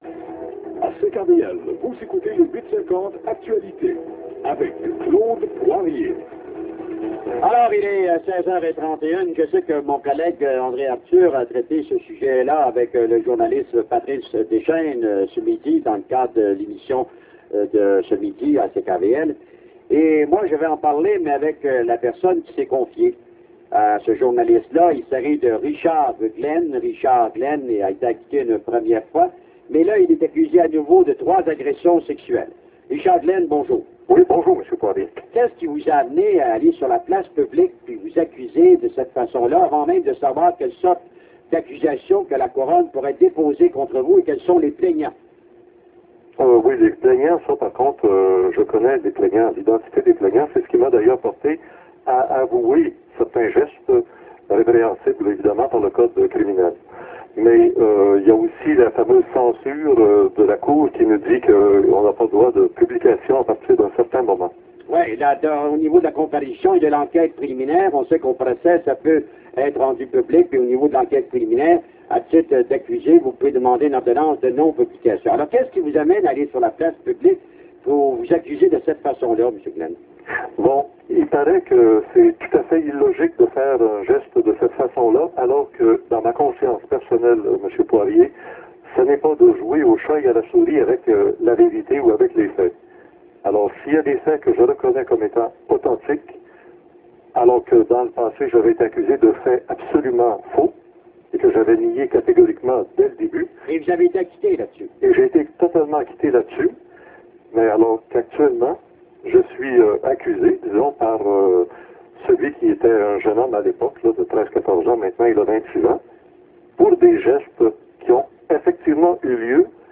ENTREVUE RADIOPHONIQUE CKVL